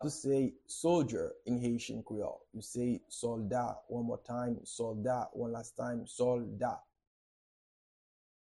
Pronunciation and Transcript:
How-to-say-Soldier-in-Haitian-Creole-Solda-pronunciation.mp3